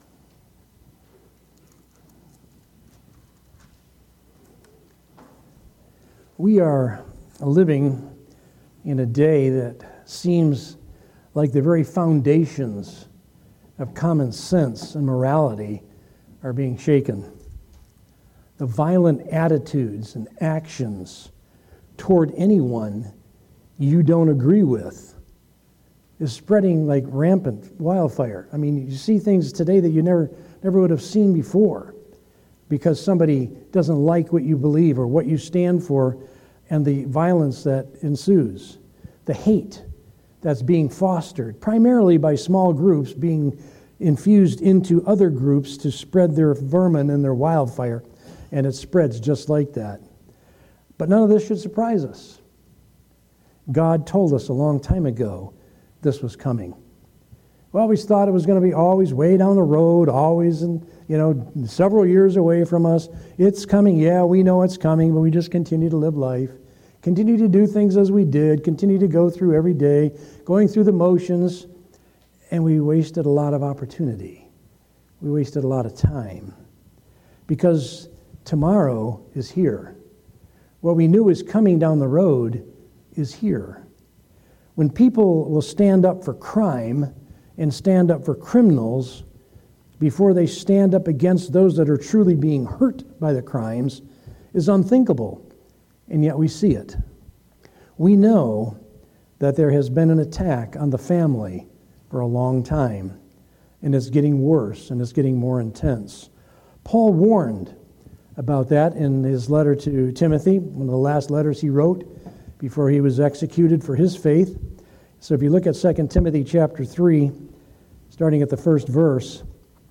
From Series: "Sunday Morning - 11:00"
Related Topics: Sermon